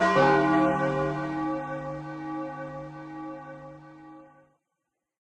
bellChime.ogg